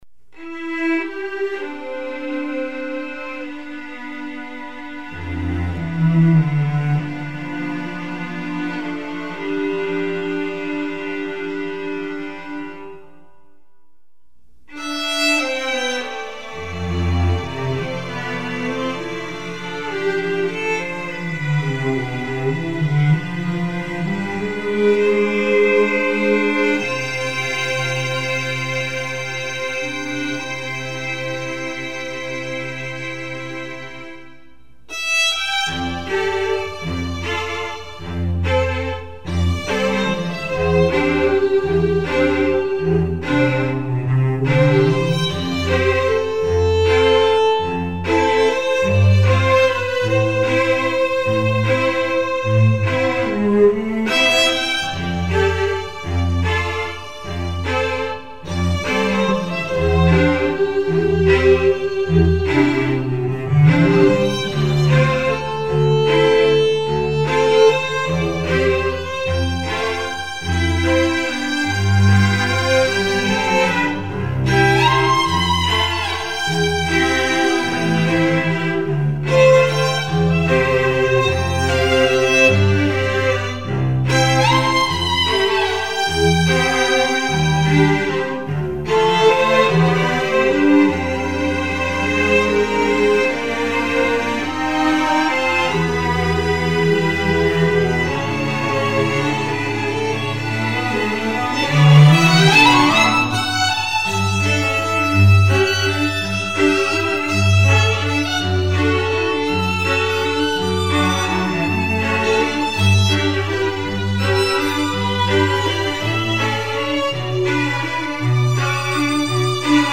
Acoustic Version